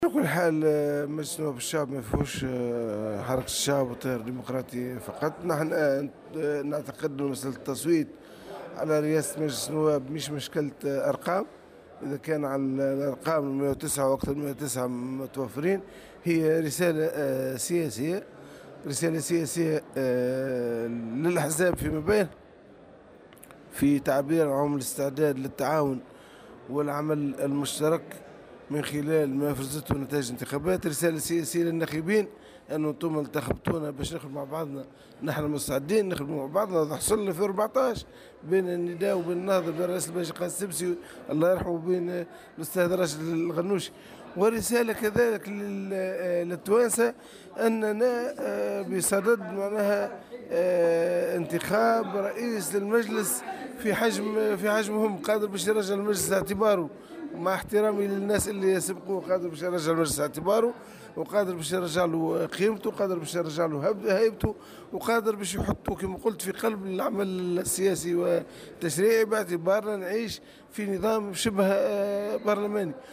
قال القيادي في حركة النهضة، نور الدين البحيري لمراسل "الجوهرة أف أم" على هامش الجلسة الافتتاحية لمجلس نواب الشعب إن الحركة رشحت رئيسها راشد الغنوشي لرئاسة البرلمان.